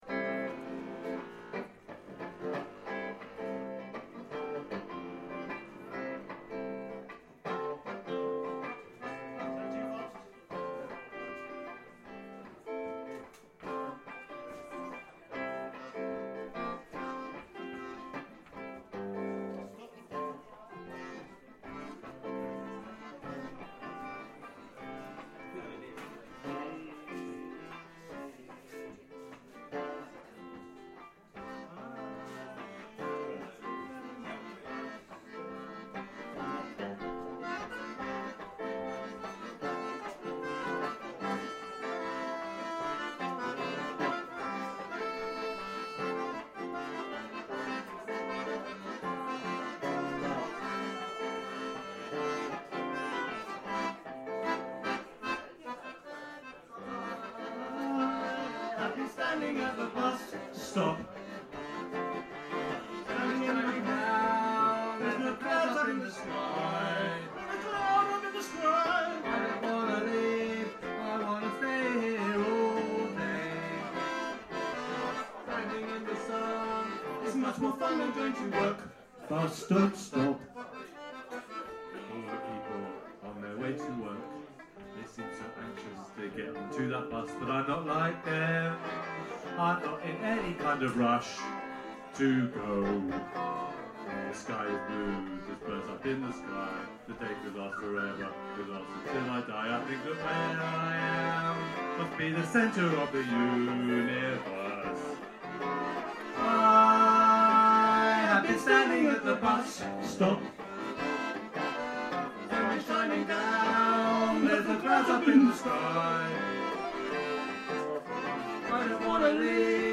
Bus Don't Stop (Live)